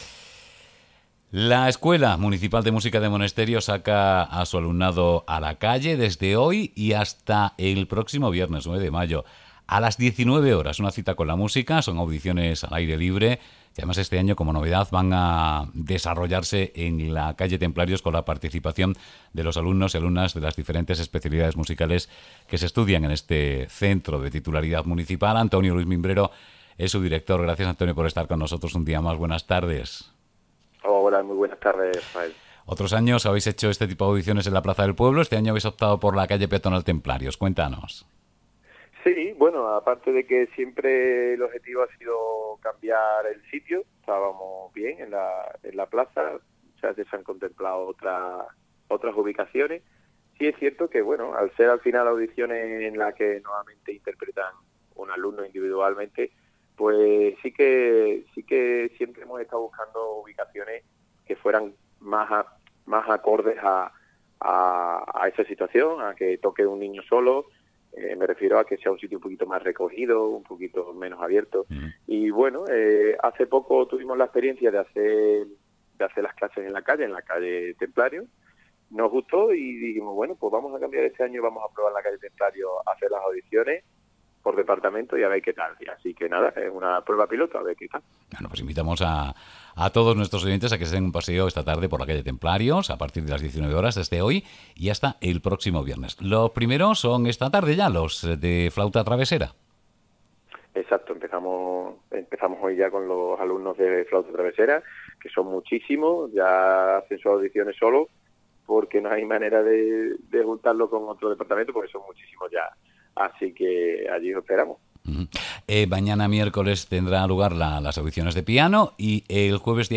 La calle Los Templarios de Monesterio acoge las audiciones de la Escuela Municipal de Música